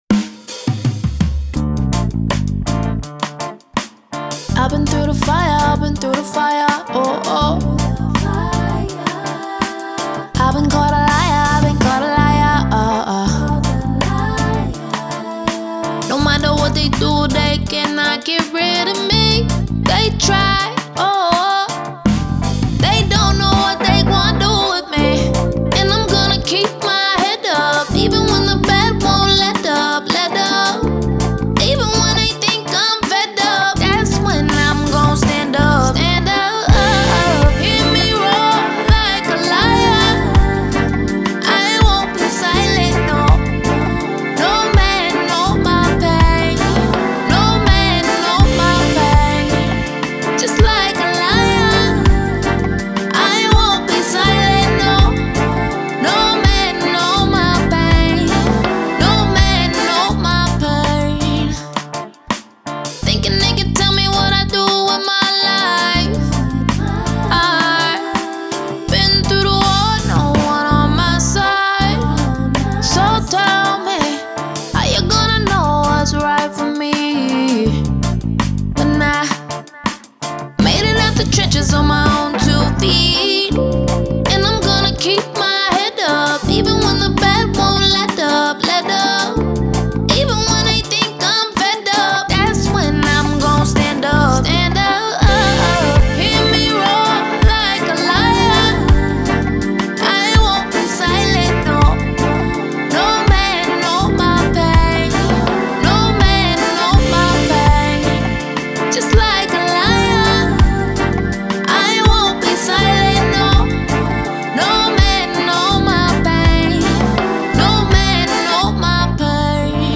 Singer/Songwriter
Afrobeat
D Minor